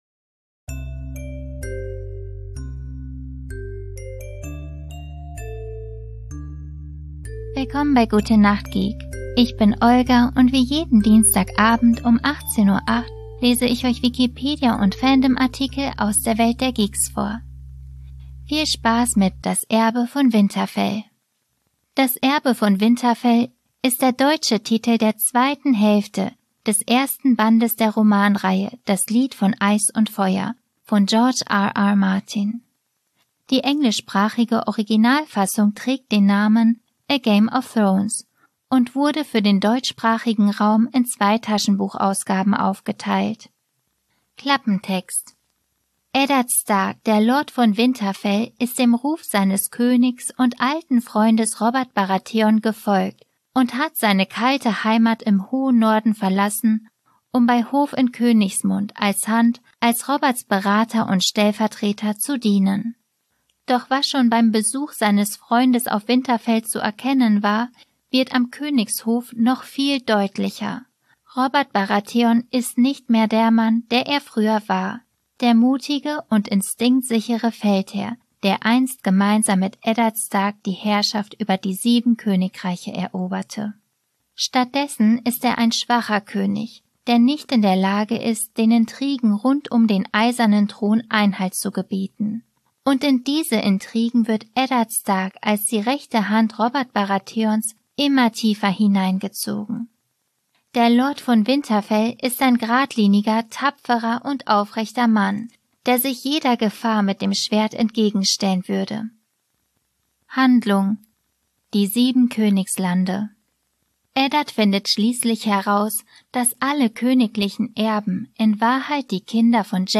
Robert Baratheon hat einen Jagdunfall, Eddard Stark gesteht einen Verrat, den er nicht begangen hat um seine Töchter Arya und Sansa zu schützen und Jon Schnee entschließt sich zu desertieren. Was mit Cercei Lennister geschieht und wie es der schwangeren Daenerys Targaryen geht, schreibe ich mal nicht... ich lese es einfach vor.